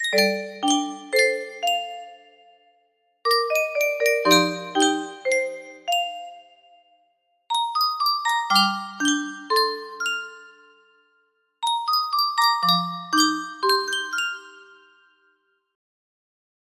l1 music box melody